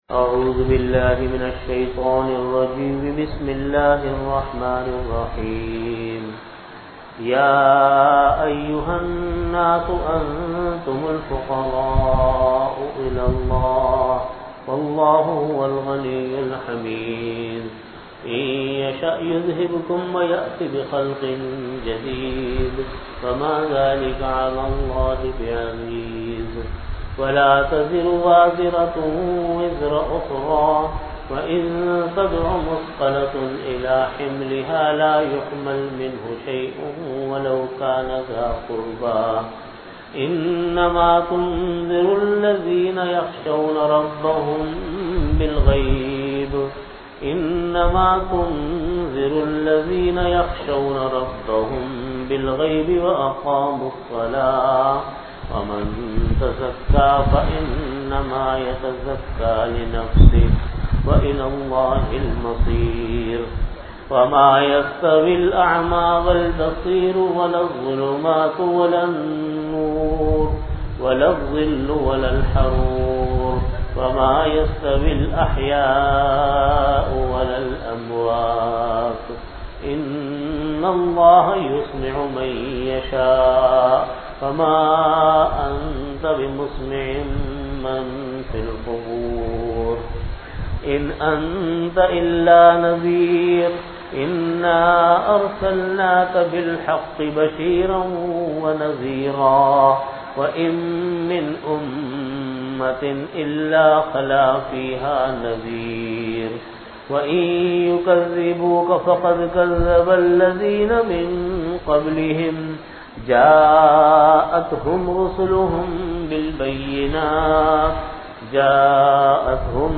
Audio Category: Tafseer
Time: After Fajr Prayer Venue: Jamia Masjid Bait-ul-Mukkaram, Karachi